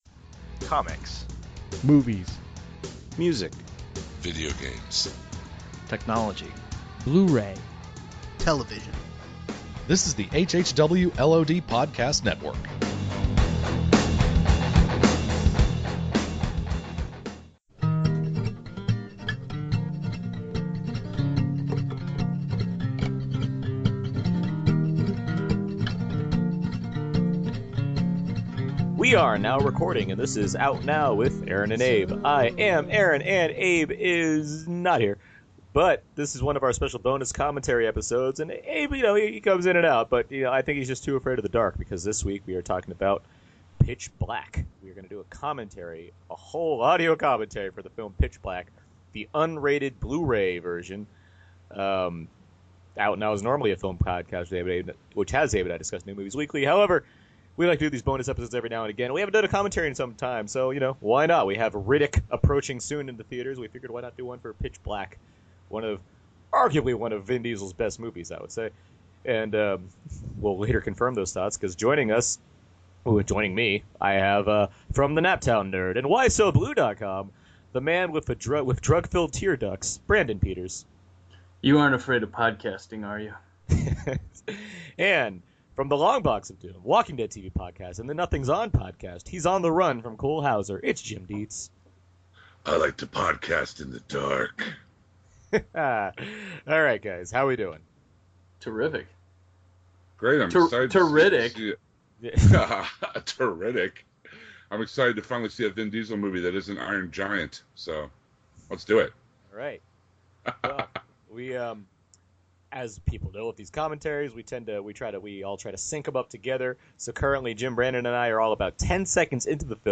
Out Now Bonus - Audio Commentary for Pitch Black